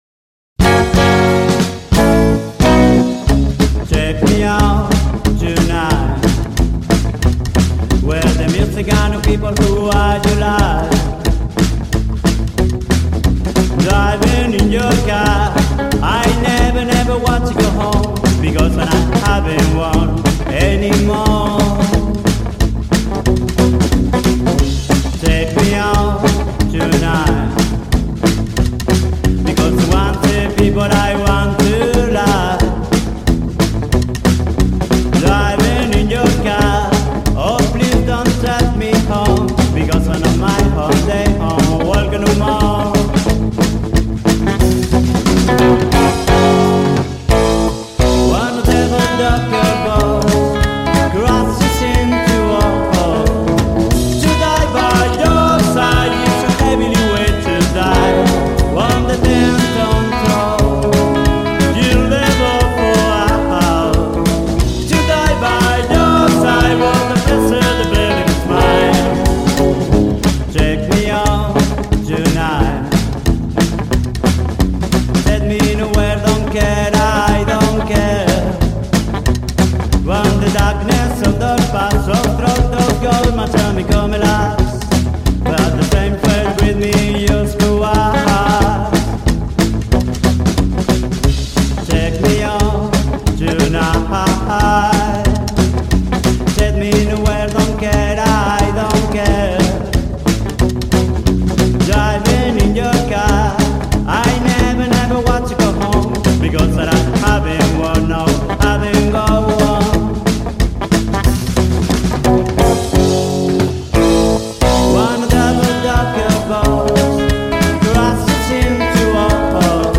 Japanese Rockabilly cover version